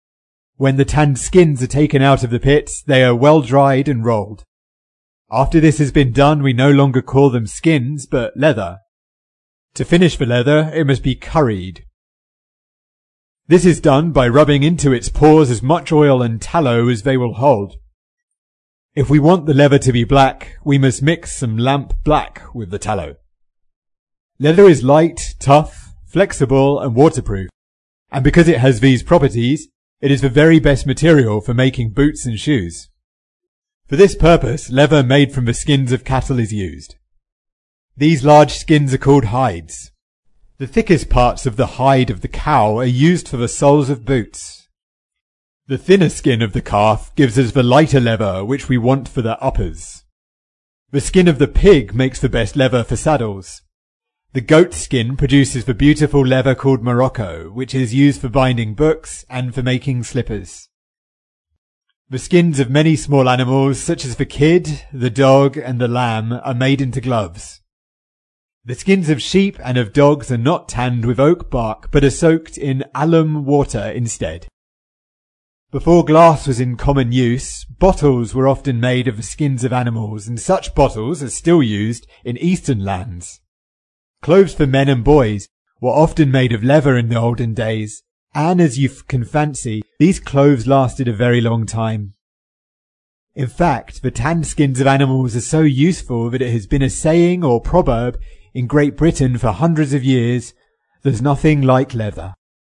在线英语听力室英国学生科学读本 第77期:百货不如皮货(2)的听力文件下载,《英国学生科学读本》讲述大自然中的动物、植物等广博的科学知识，犹如一部万物简史。在线英语听力室提供配套英文朗读与双语字幕，帮助读者全面提升英语阅读水平。